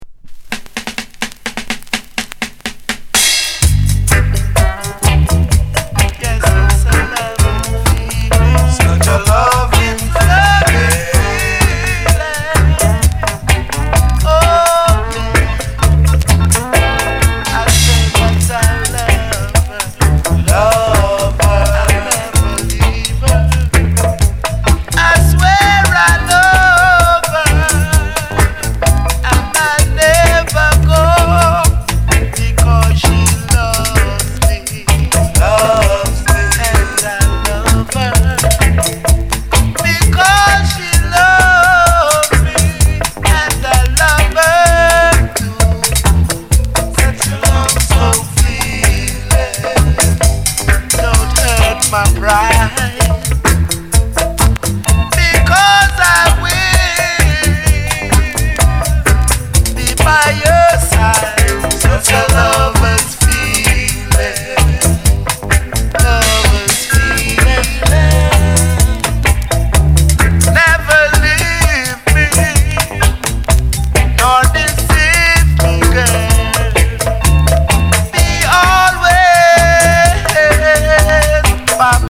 Genre: Reggae /Roots